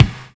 NY 1 BD.wav